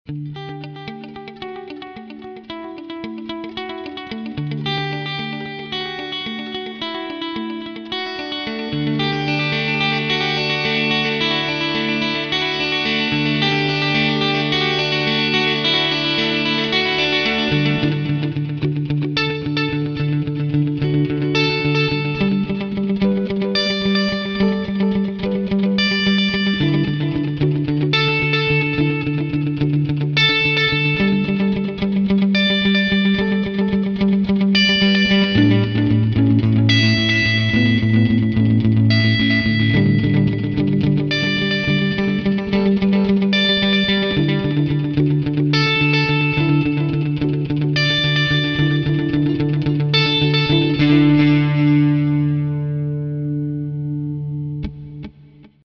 AMPCleanAmbiancePong.mp3